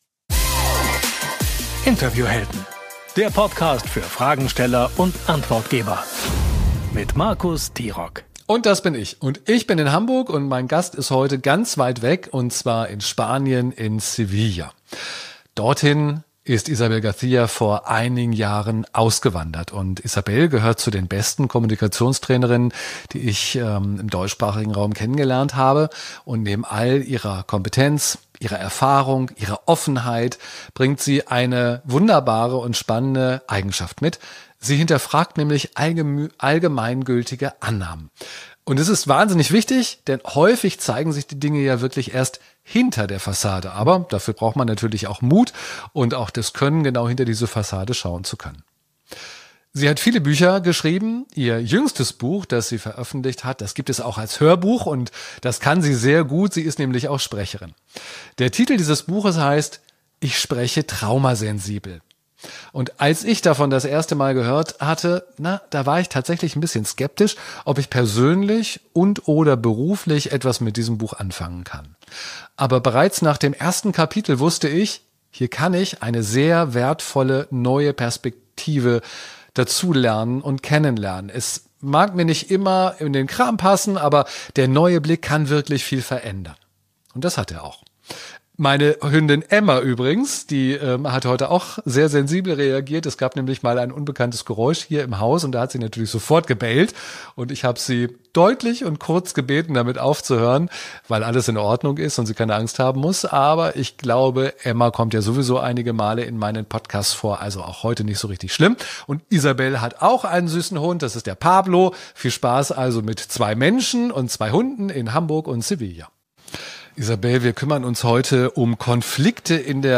Interviewhelden